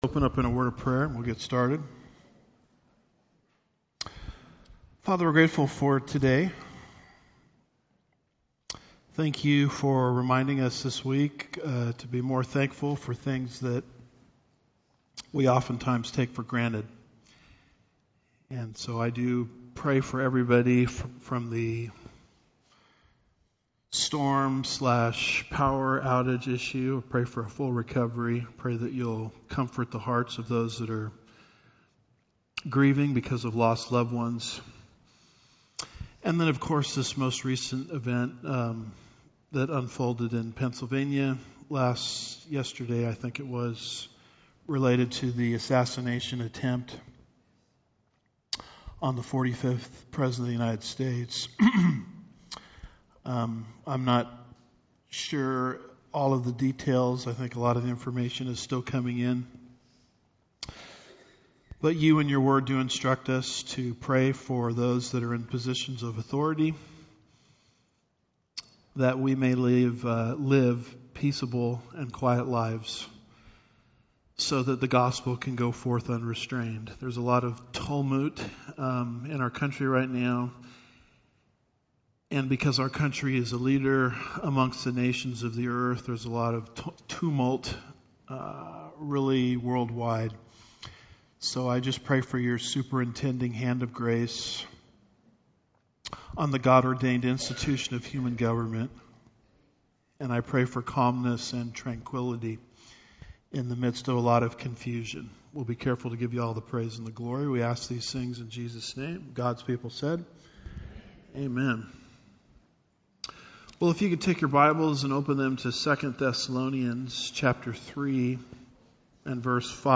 Home / Sermons / Societal Unrest: How Should the Christian Respond?